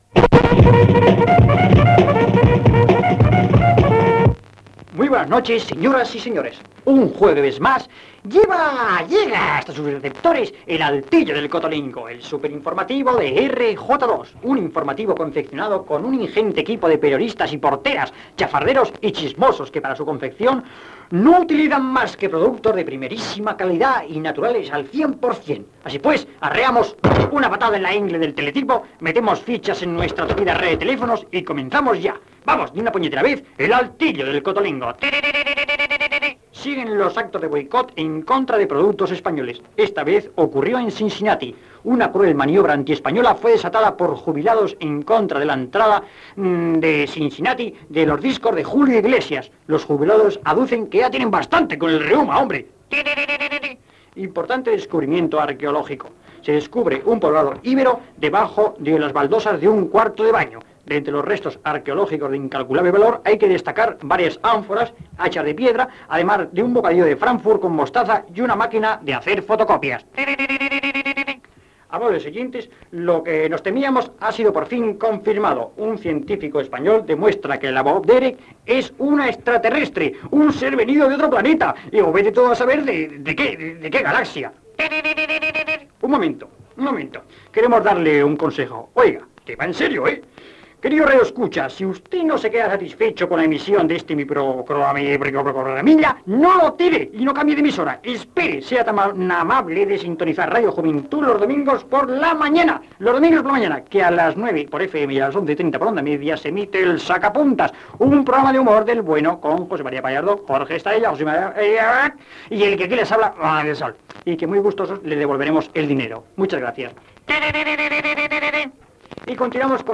"El altillo del cotolengo", informatiu humorístic, amb anunci de l'emissió de "El Sacapuntas"
Entreteniment
FM